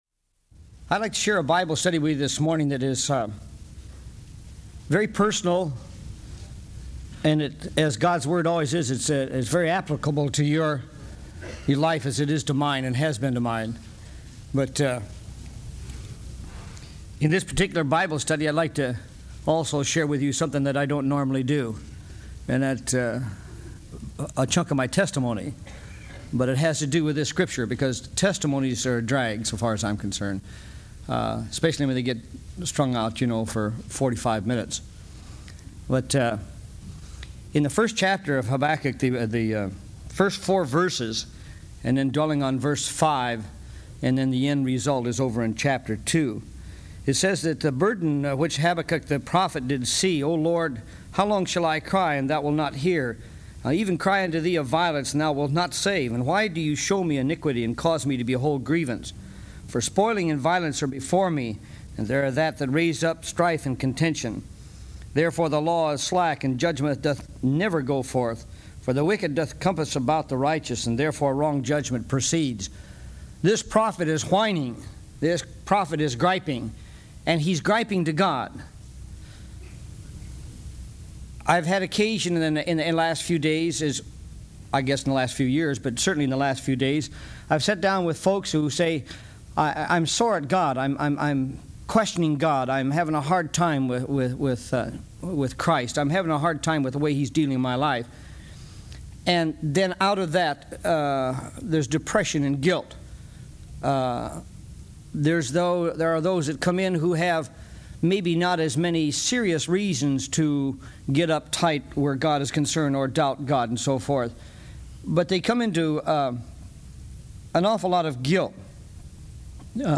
Griping Before God download sermon mp3 download sermon notes Welcome to Calvary Chapel Knoxville!